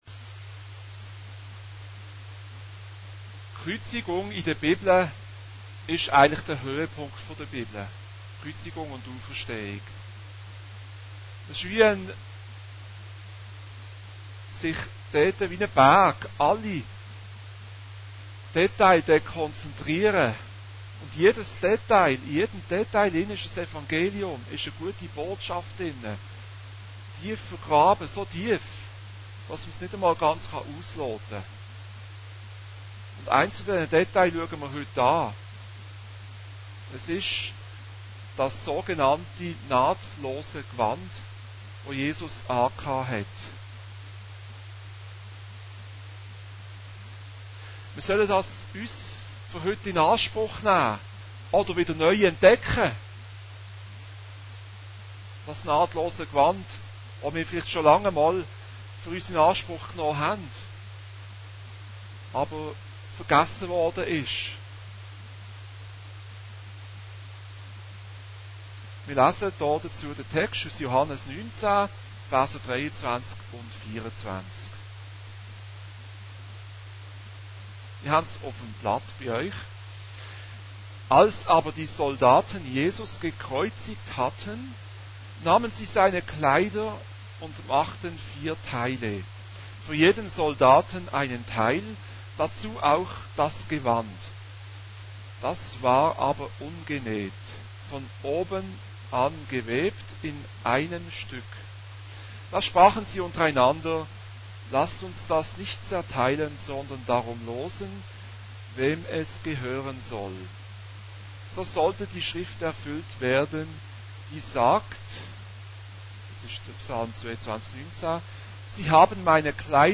Allgemeine Predigten Date